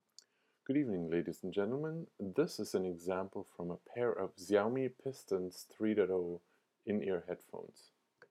Xiaomi Piston 3wired IEM, now 1More Piston